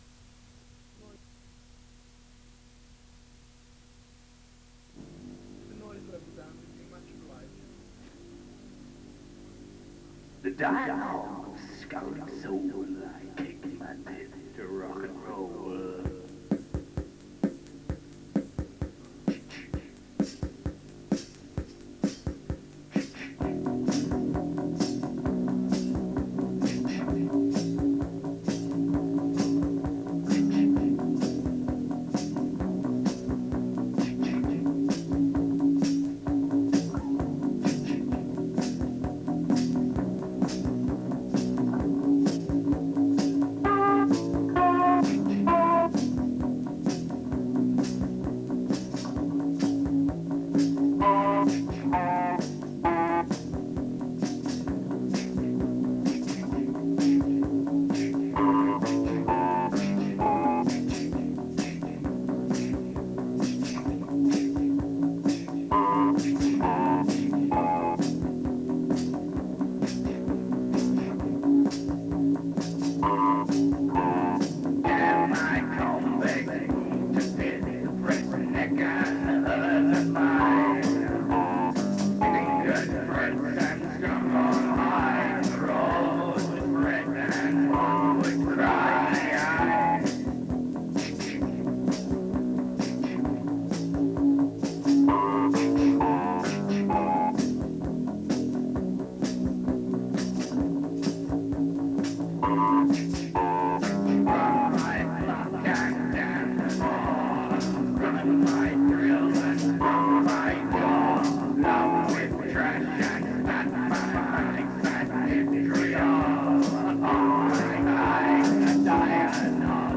Mes excuses pour le souffle.